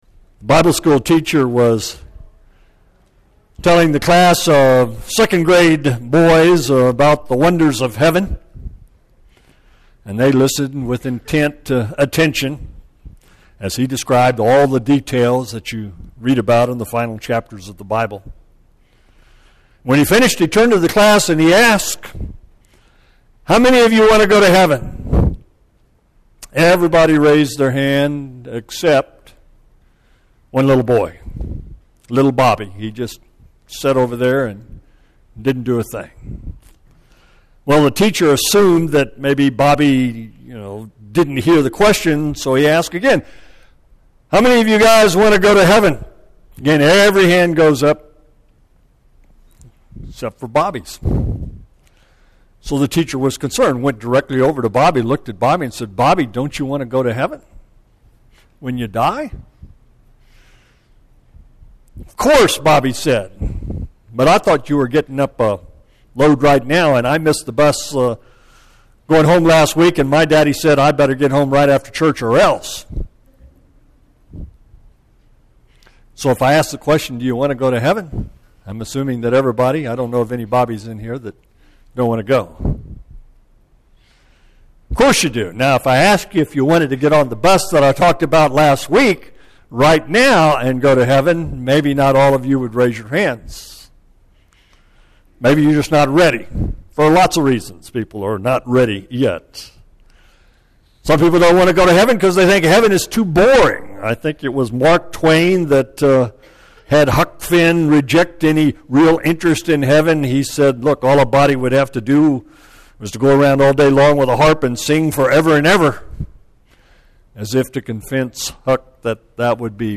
Audio Sermons - Babcock Road Christian Church